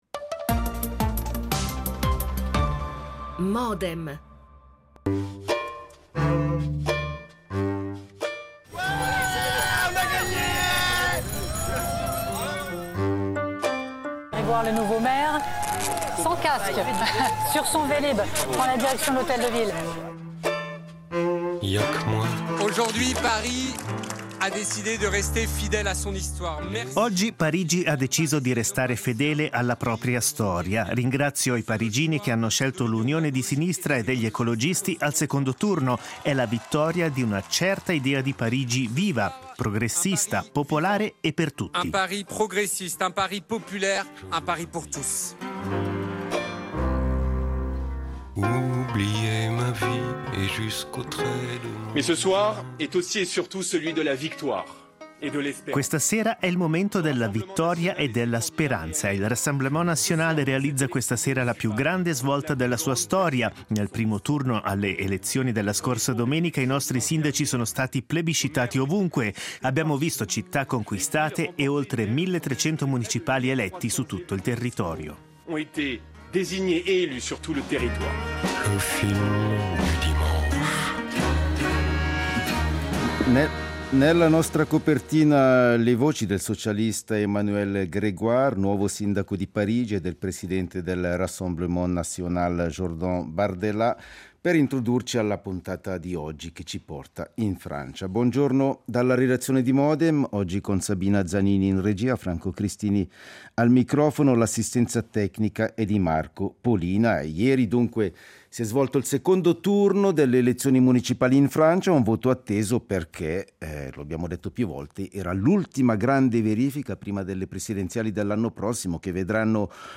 politologo Scopri la serie Modem L'attualità approfondita, in diretta, tutte le mattine, da lunedì a venerdì https